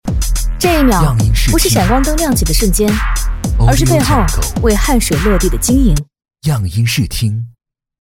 女B32-TVC - 【潮酷】
女B32-大气质感 成熟厚重
女B32-TVC - 【潮酷】.mp3